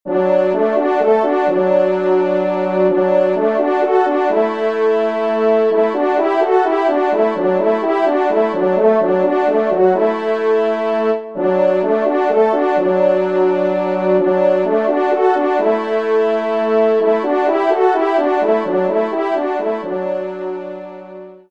Genre :  Divertissement pour Trompes ou Cors
2ème Trompe